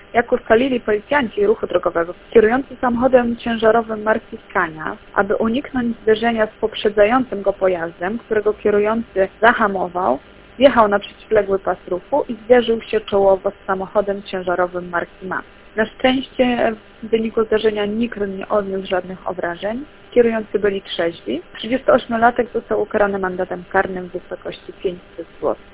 w rozmowie z Radiem 5